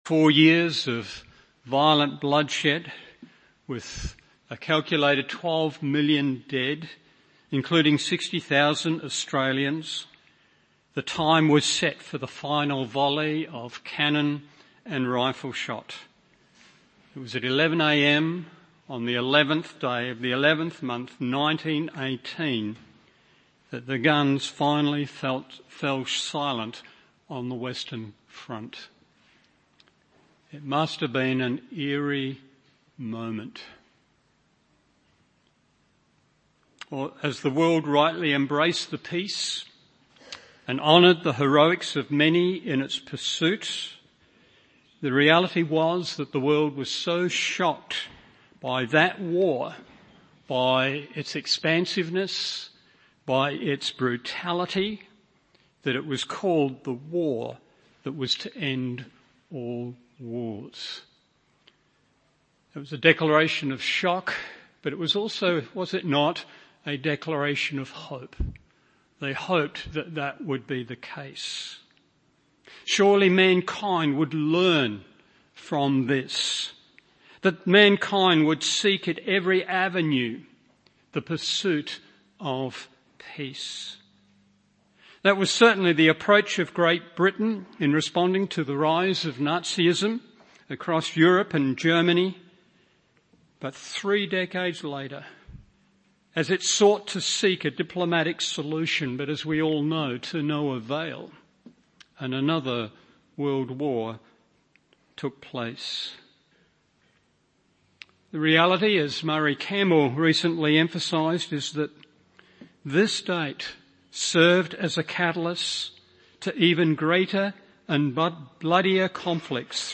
Morning Service Genesis 9:18-29 1.